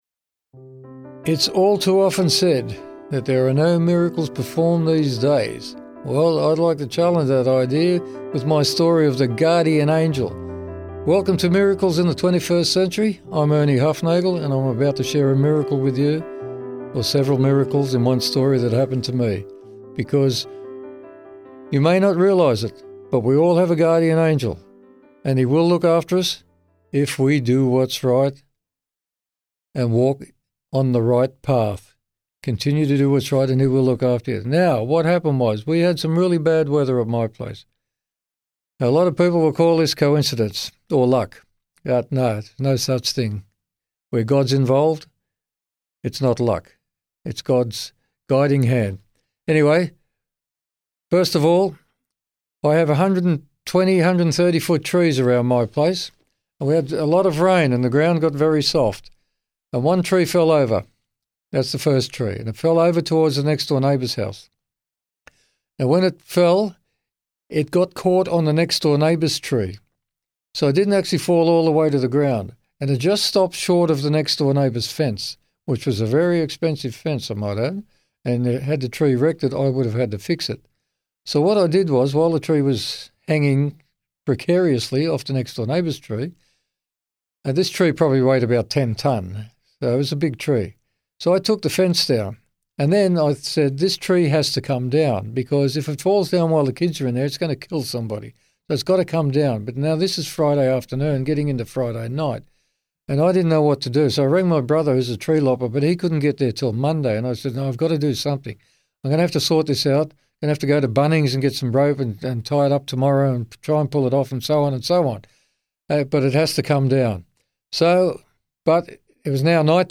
In this miracle testimony, you'll discover: